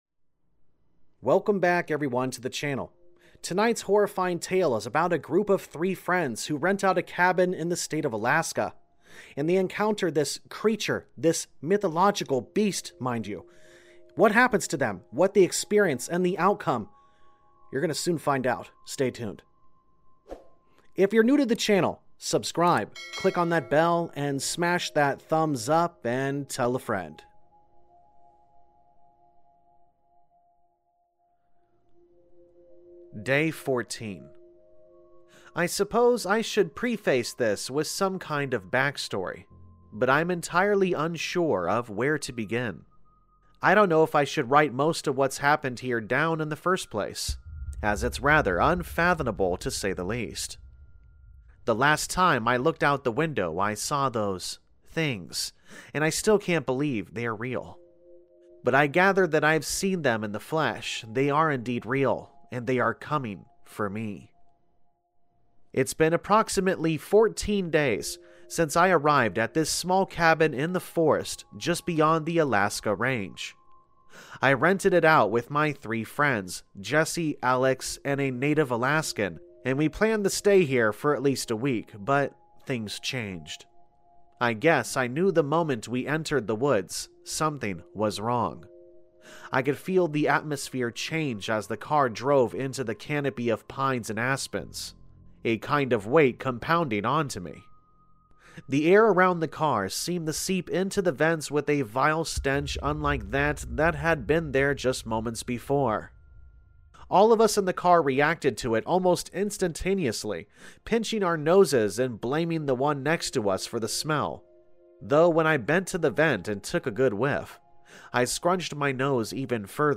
All Stories are read with full permission from the authors